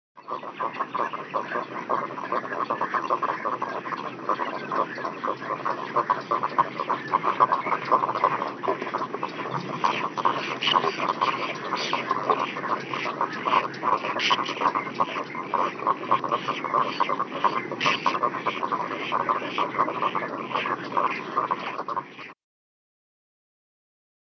Little Pied Cormorant
Songs & Calls
little-pied-cormorant-web.mp3